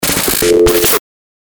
FX-942-PHONE-BREAKER
FX-942-PHONE-BREAKER.mp3